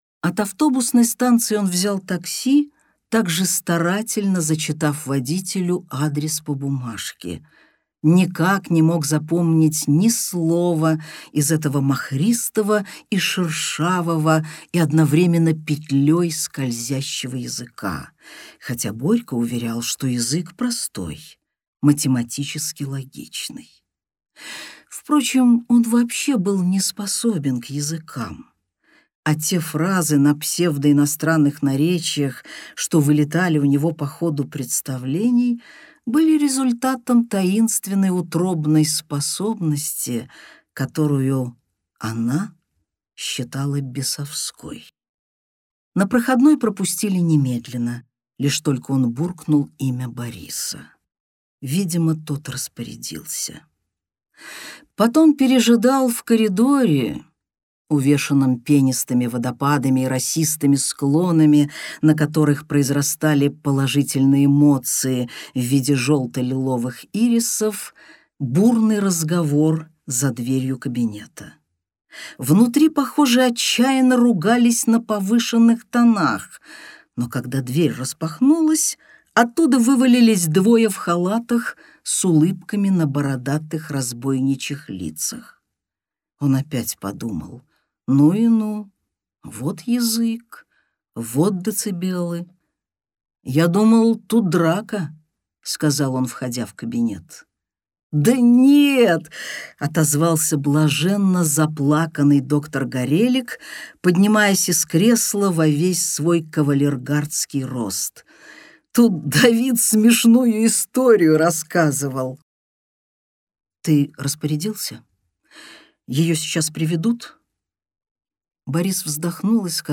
Аудиокнига Синдром Петрушки | Библиотека аудиокниг
Aудиокнига Синдром Петрушки Автор Дина Рубина Читает аудиокнигу Дина Рубина.